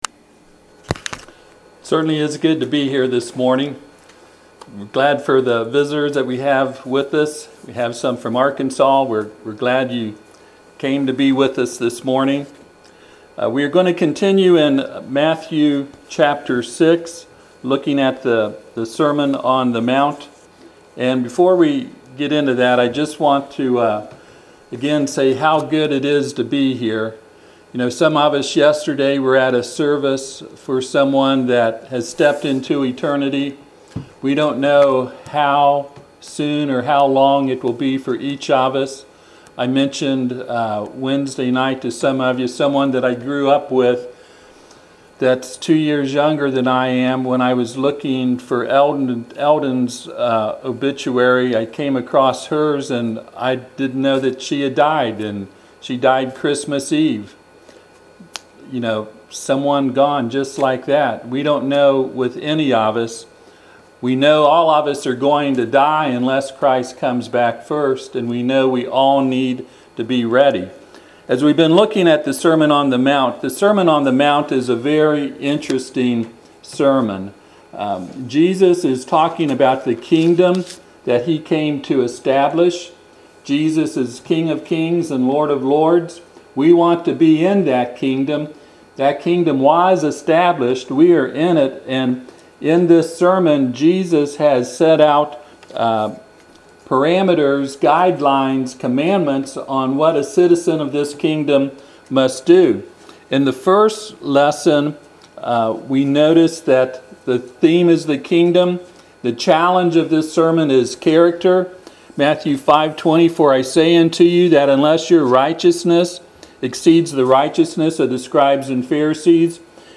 Passage: Matthew 6:1-18 Service Type: Sunday AM